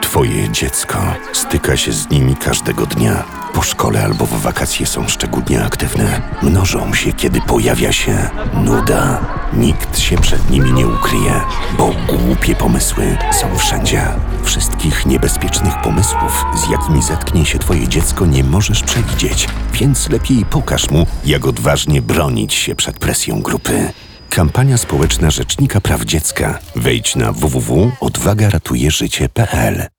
odwaga_ratuje_zycie_kampania_spoleczna_rpd_spot_radiowy.wav